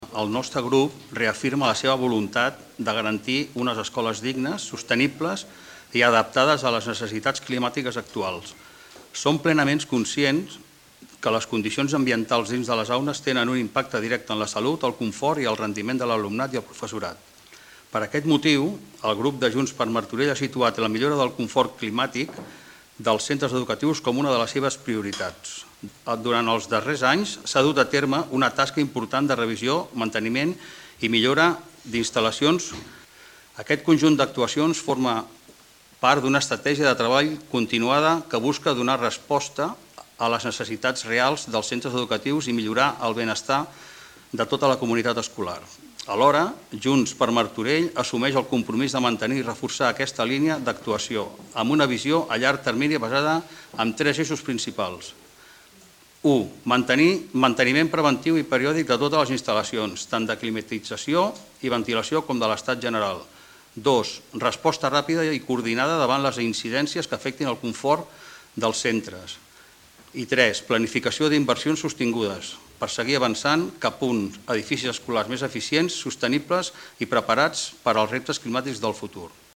Així es va acordar, per unanimitat, en una moció presentada al Ple Municipal d’aquest dilluns.
Mario Céspedes, regidor d'Habitatge, Rehabilitació i Equipaments Municipals